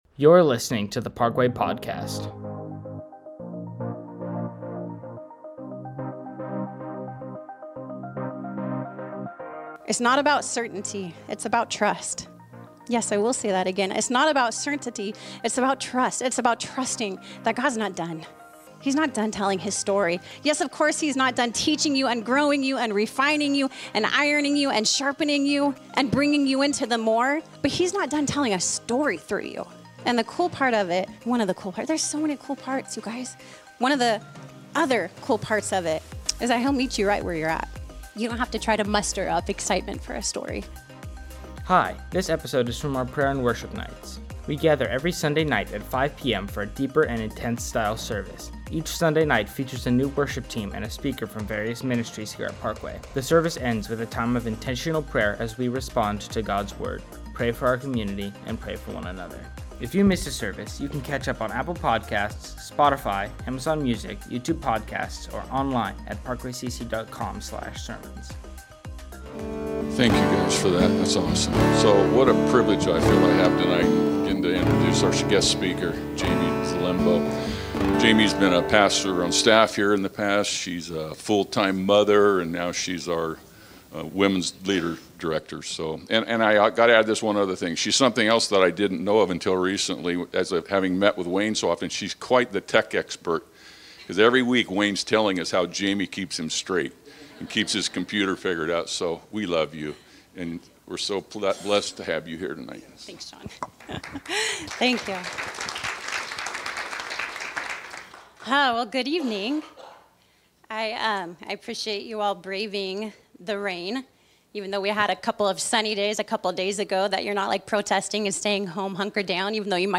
A message from the series "Prayer & Worship Nights."